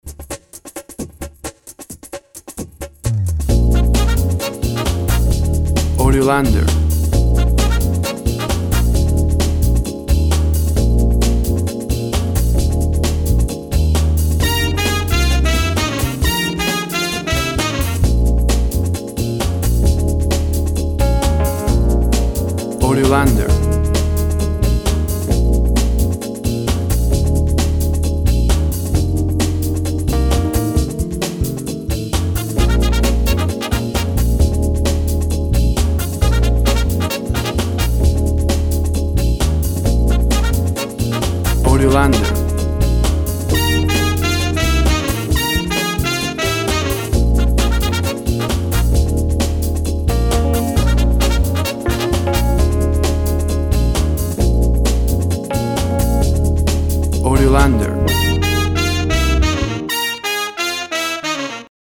Pop dance groove, with power brass section.
Tempo (BPM) 120